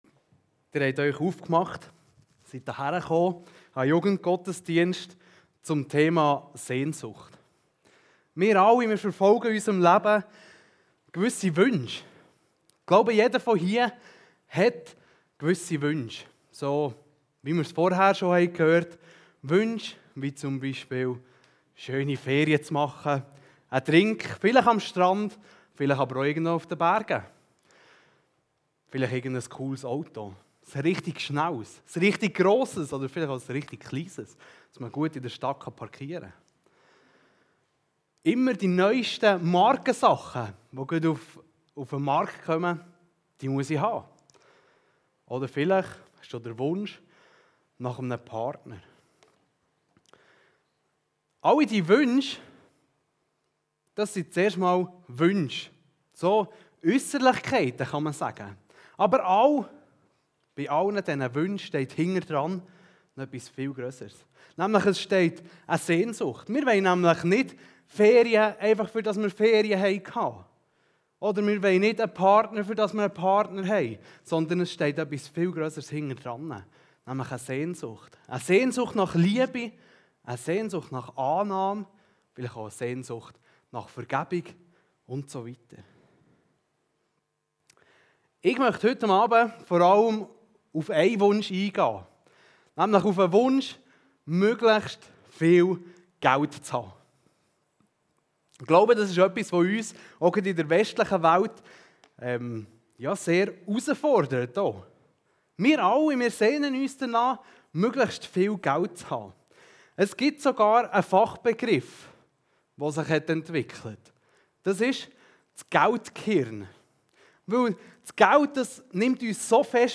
Kategorie: Predigt